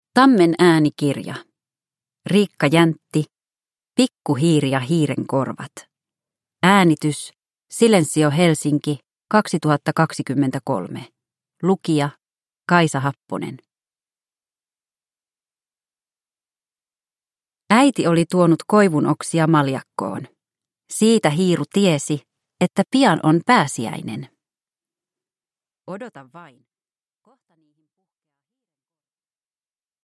Pikku hiiri ja hiirenkorvat – Ljudbok – Laddas ner